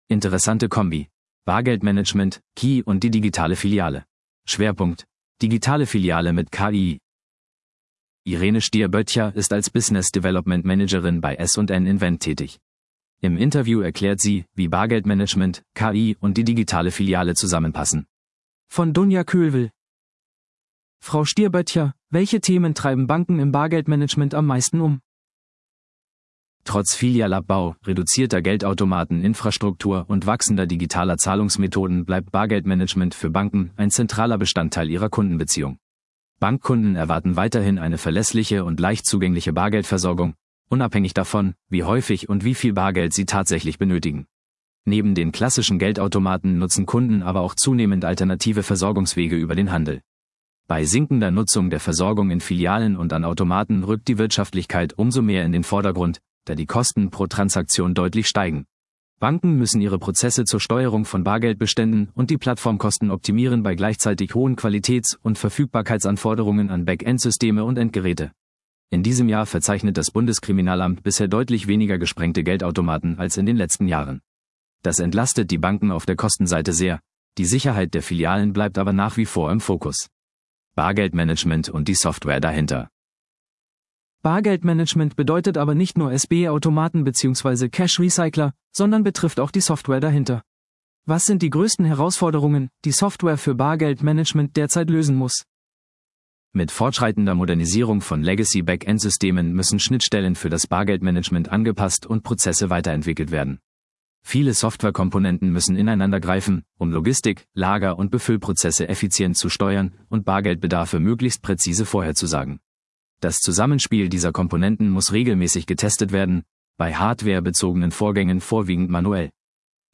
Im Interview erklärt sie, wie Bargeldmanagement, KI und die digitale Filiale zusammenpassen.